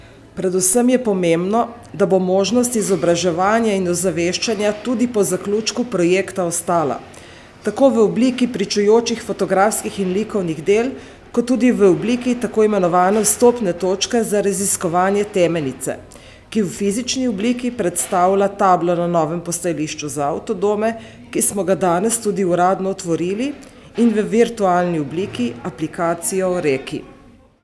Osrednja prireditev ob 2. Dnevu Temenice je potekala v dvorani STIK. Zbrane je nagovorila županja Občine Trebnje Mateja Povhe in med drugim poudarila: